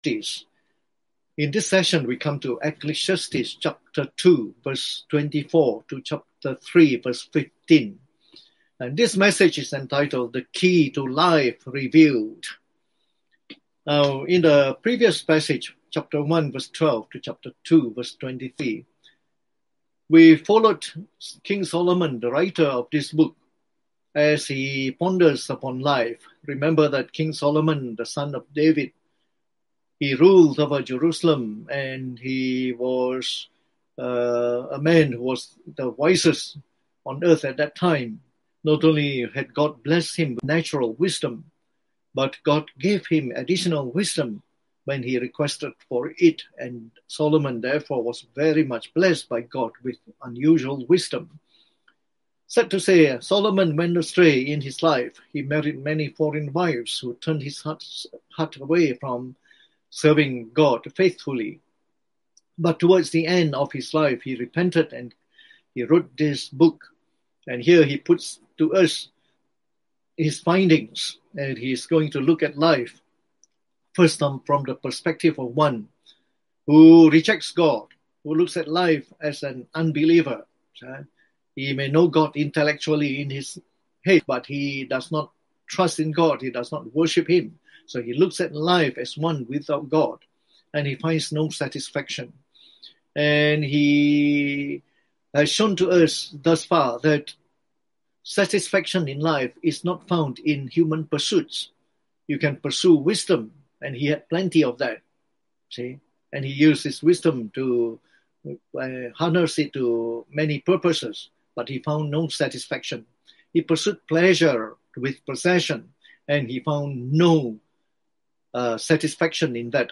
Preached on the 5th of July 2020.
delivered online in the Evening Service